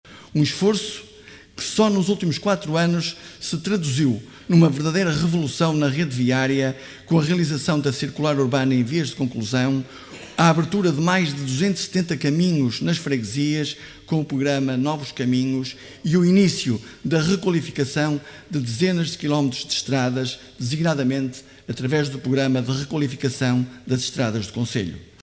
Município de Barcelos celebrou 25 de Abril em sessão solene
O Município de Barcelos assinalou no sábado o 52.º aniversário da Revolução de Abril com uma sessão solene realizada no Auditório dos Paços do Concelho, num momento de evocação que reuniu as diversas forças políticas em torno dos valores da liberdade e da construção do futuro.